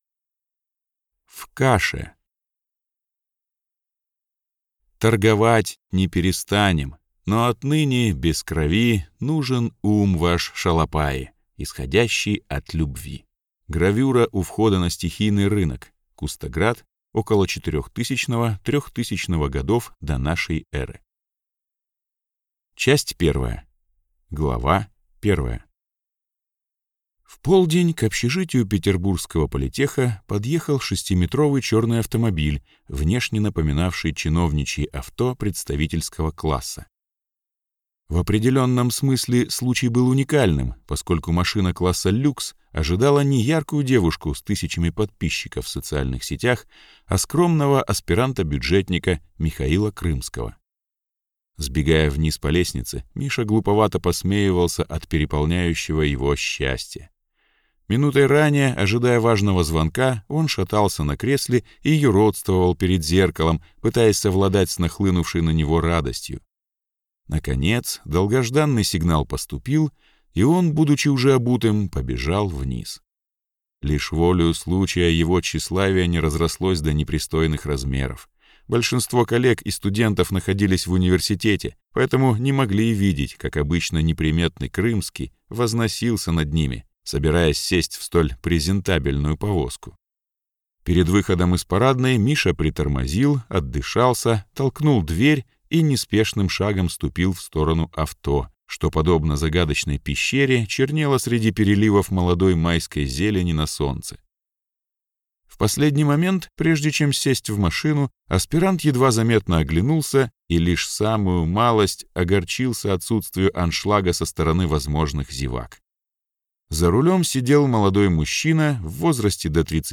Aудиокнига В каше